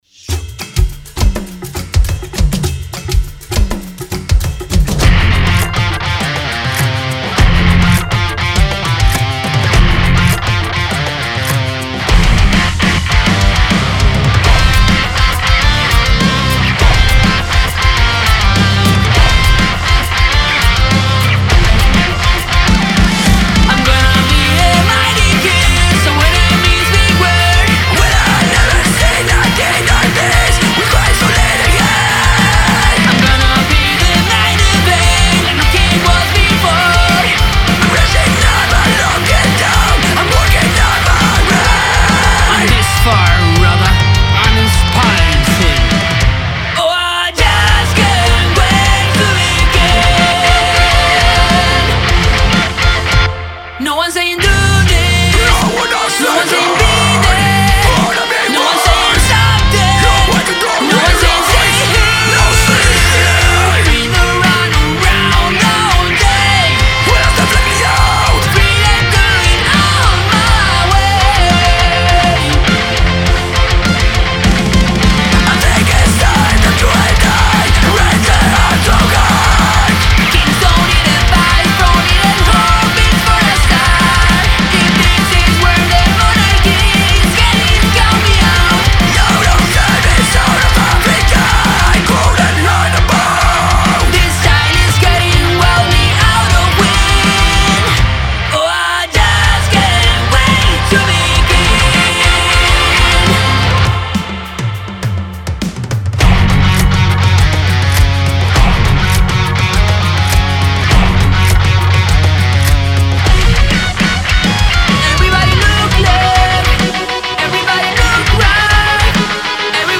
Metal cover